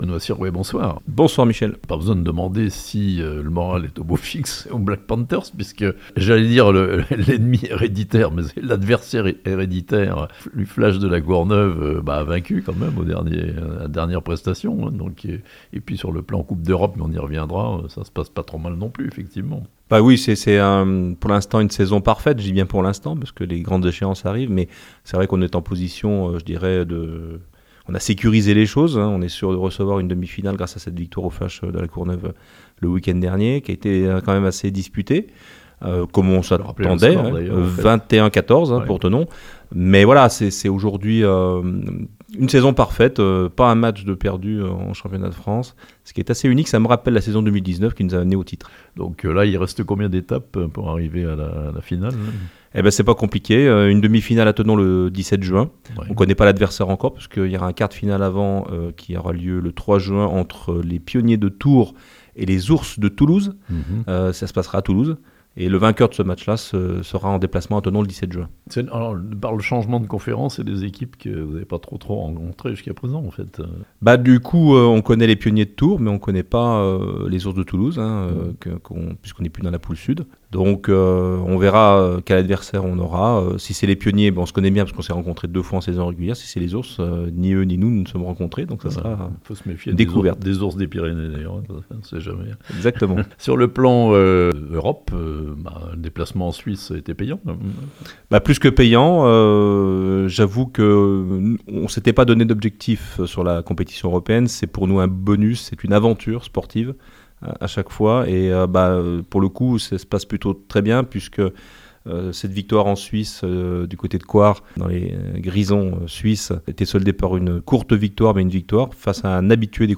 Tout roule, pour l'instant, pour les footballeurs américains de Thonon (interview)